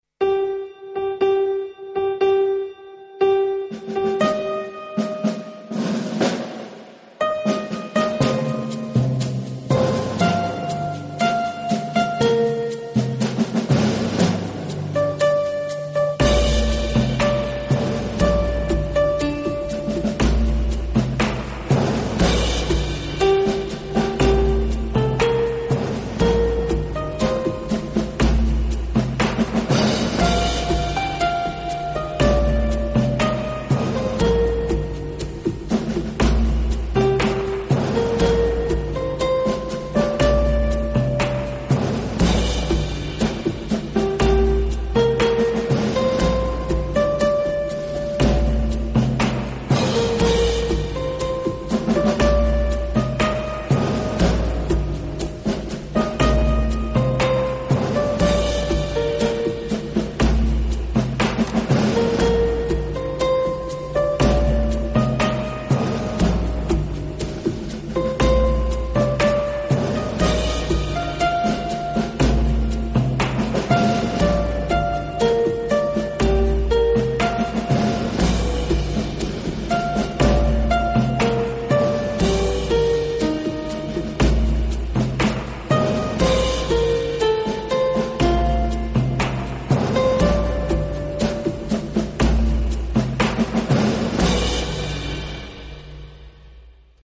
Бакъан